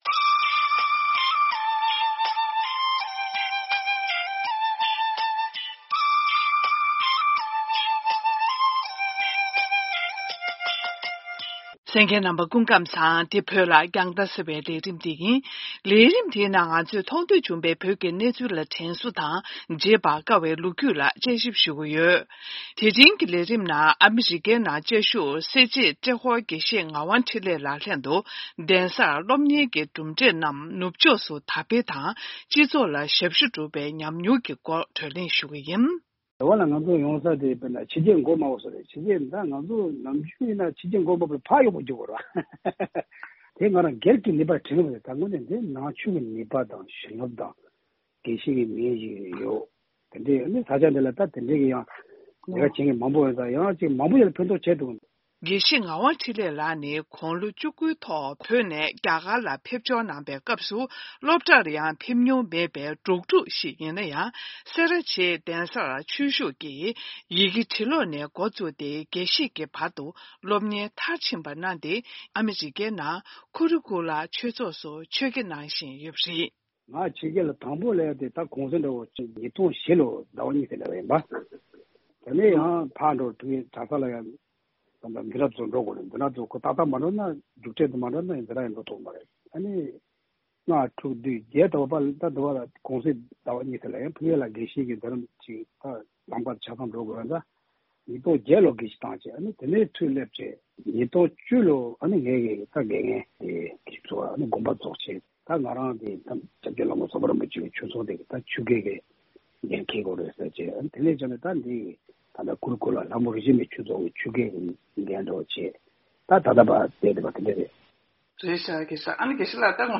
གླེང་མོལ